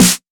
Chart Snare 02.wav